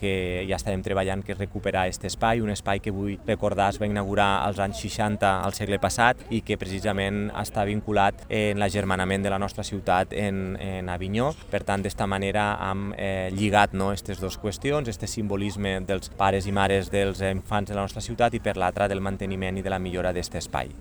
L’alcalde de Tortosa, Jordi Jordan, ha destacat que aquesta plantació és una manera simbòlica de recuperar i preservar el Roser d’Avinyó.